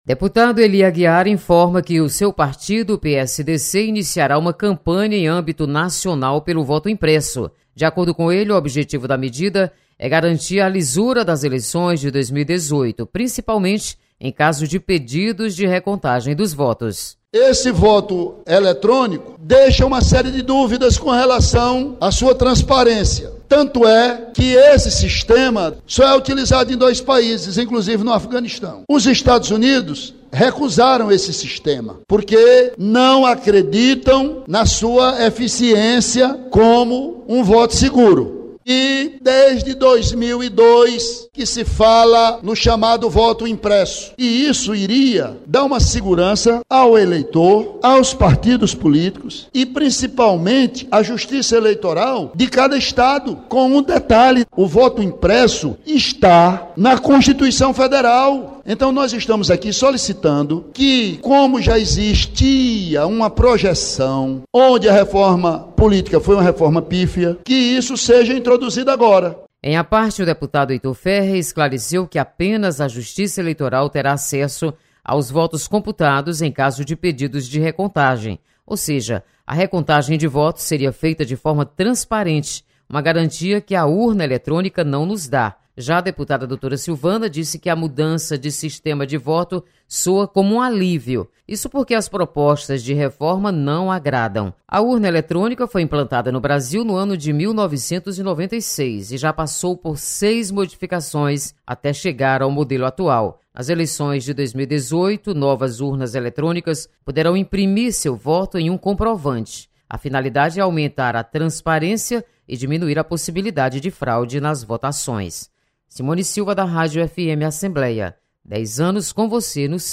Deputados comentam sobre mudança no sistema de voto.